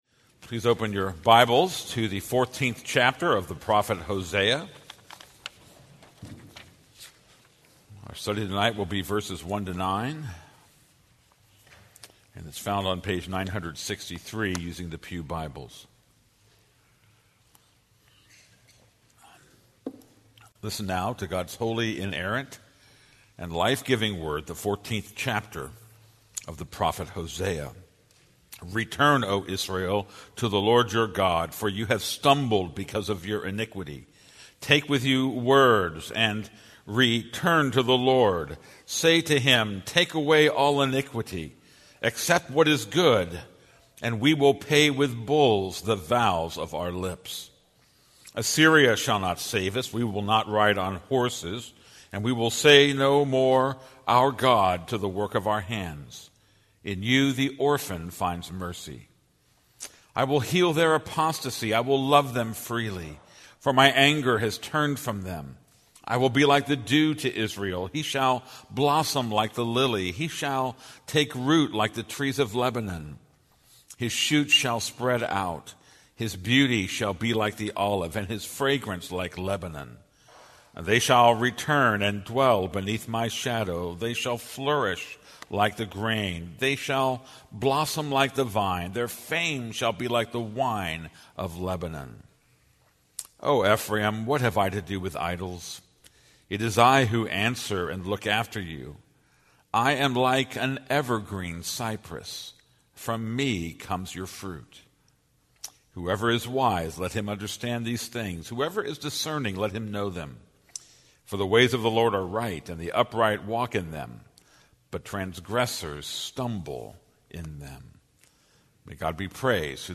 This is a sermon on Hosea 14:1-9.